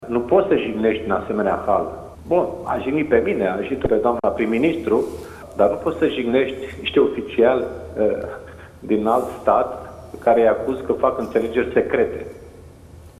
Între timp, de la Bacău, președintele a susținut că peremierul a plecat în vizită oficială fără mandat să discute cu oficialii israelini în numele României și a reclamat secretomania din jurul acestei vizite. Răspunsul a venit din partea liderului PSD, Liviu Dragnea, într-o intervenție la Antena 3:
27apr-11-Dragnea-nu-poti-sa-jignesti-asa.mp3